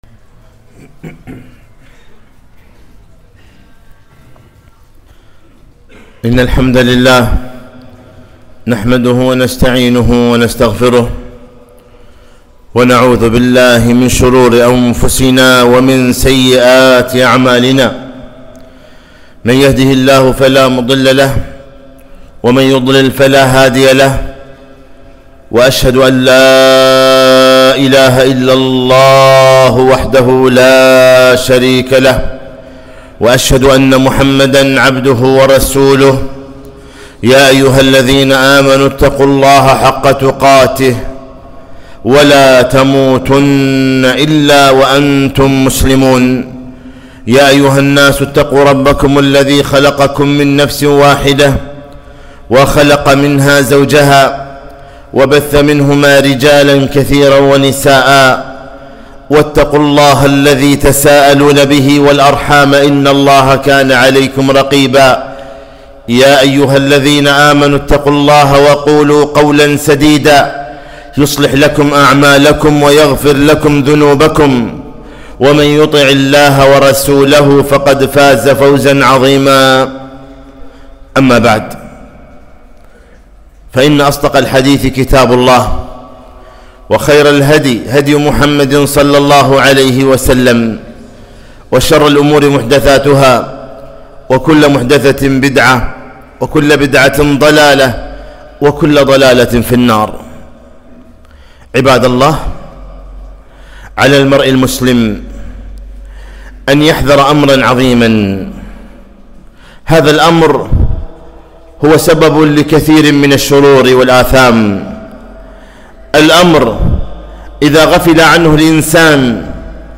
خطبة - خطر الهوى